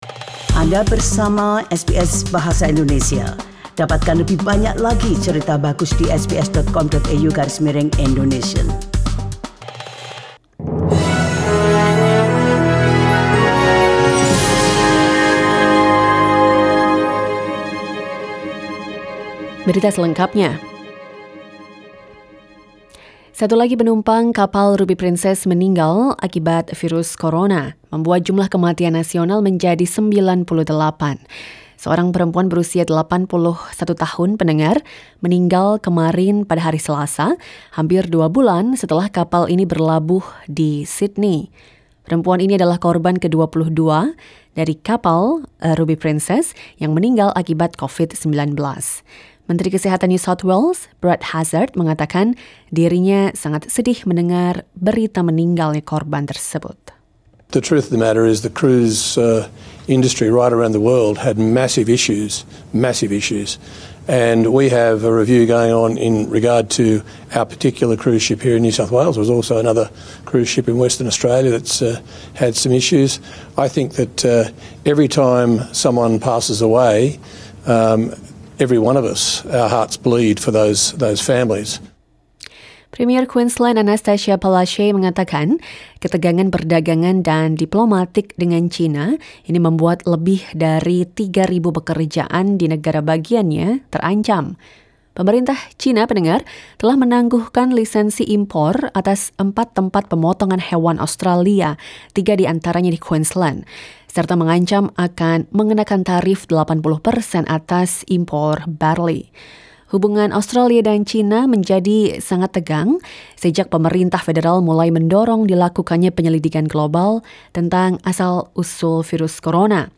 SBS Radio news in Indonesian - 13 May 2020